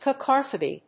音節ca・cog・ra・phy 発音記号・読み方
/kækάgrəfi(米国英語)/